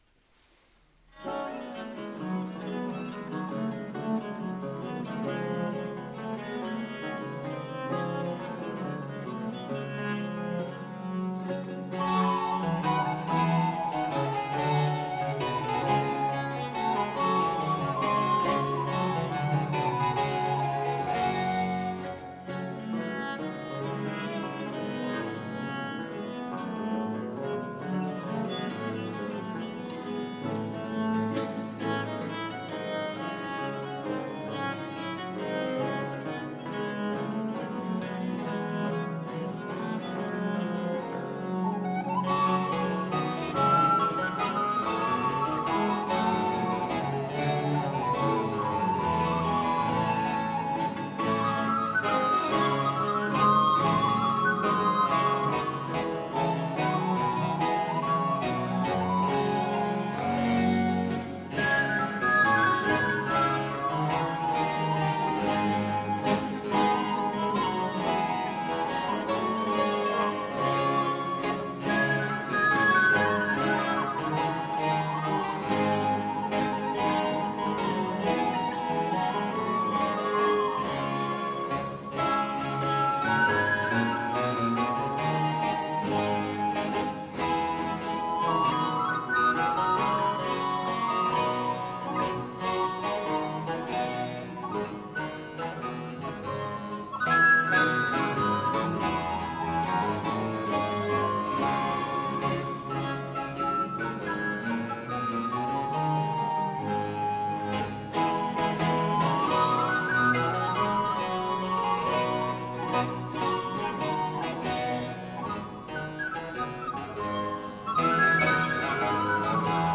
Avec l’ensemble vocal « Reflets », dix sept chanteurs, et l’ensemble instrumental « Escapades », six musiciens qui jouent des instruments anciens, flûtes à bec, violes de gambe, clavecin, théorbe.